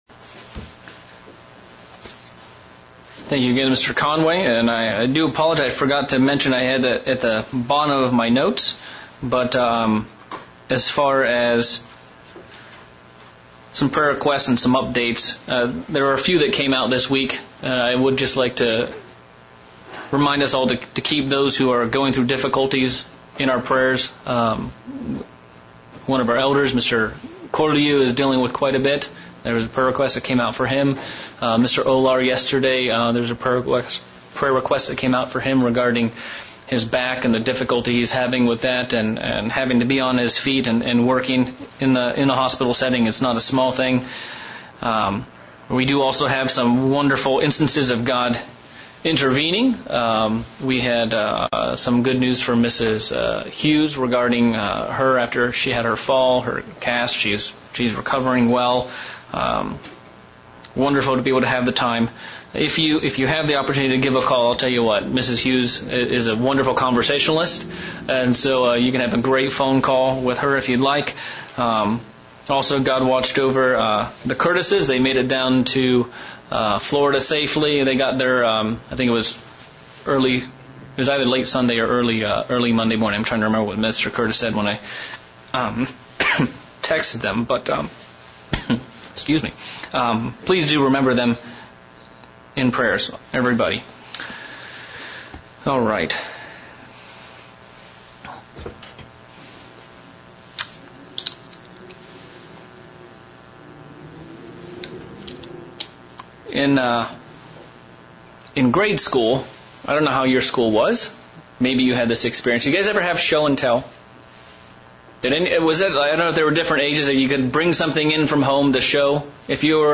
Given in Central Illinois